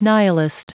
Prononciation du mot nihilist en anglais (fichier audio)
Prononciation du mot : nihilist